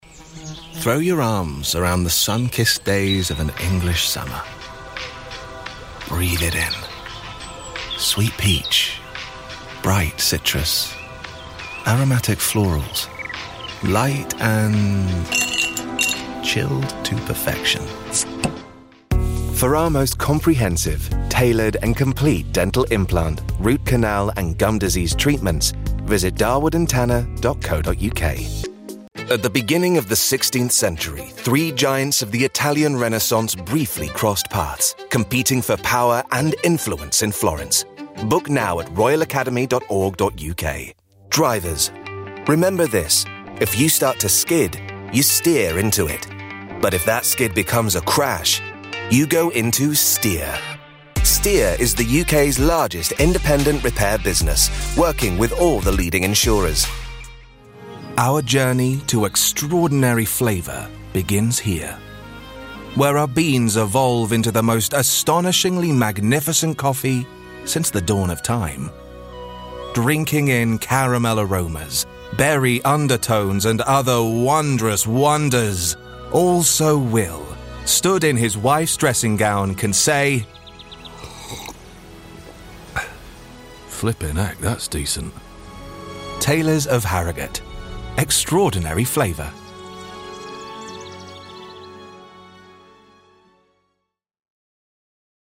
English (British)
I’m an award-winning voiceover actor with a broadcast-quality home studio.
Trustworthy
Warm
Engaging